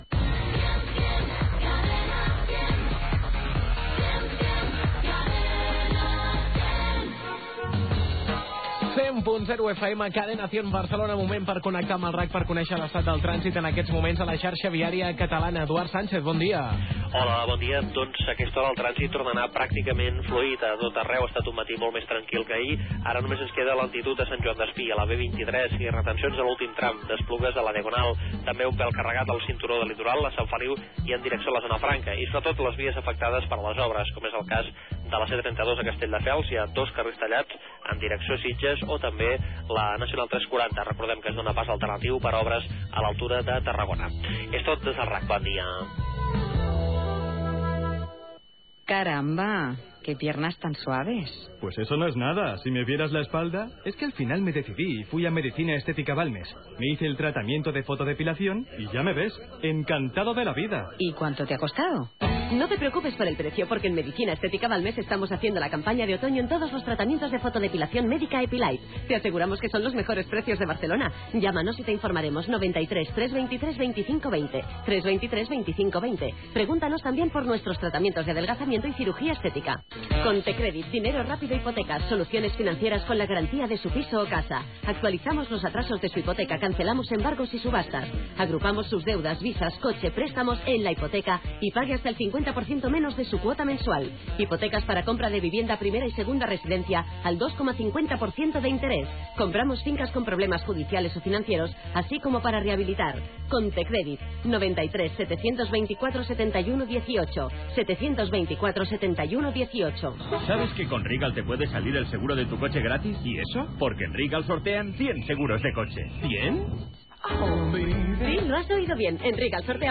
Indicatiu de la ràdio, connexió amb el RAC per rebre informació del trànsit, publicitat
FM